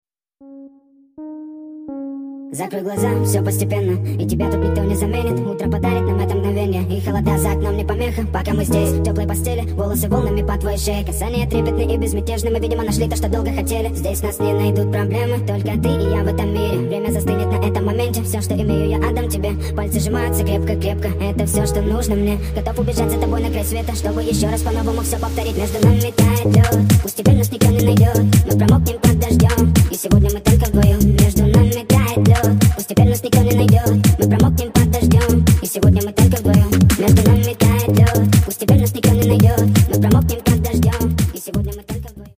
Танцевальные рингтоны / Романтические рингтоны